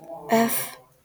IPA[œf] ?/i, lm [ø]
Końcowe ż wymawiane jest tylko w liczbie pojedynczej (patrz: wymowa).